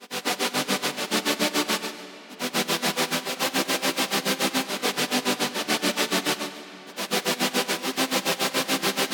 面倒だったので使い回しのコードですが、変化はしっかりわかりますね。
本格的なLFOです。
Nexus-例-LFO.mp3